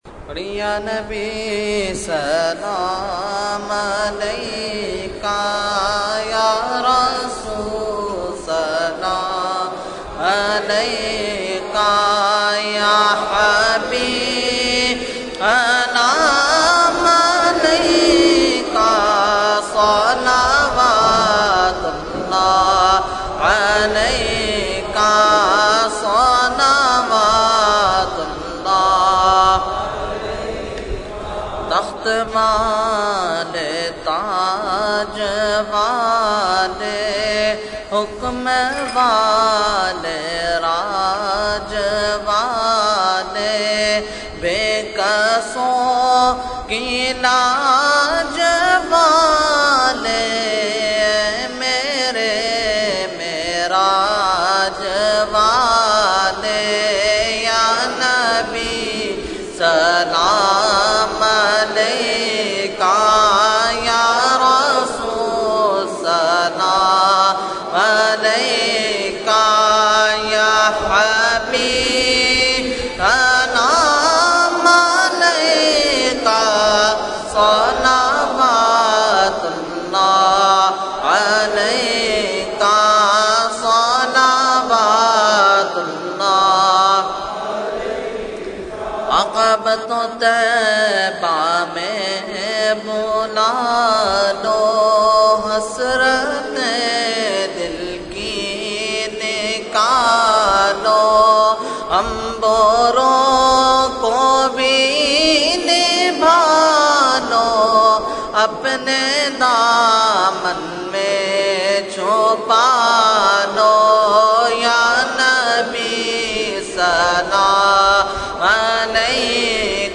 Category : Salam | Language : UrduEvent : Dars Quran Ghousia Masjid 24 June 2012